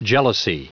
Prononciation du mot jealousy en anglais (fichier audio)
Prononciation du mot : jealousy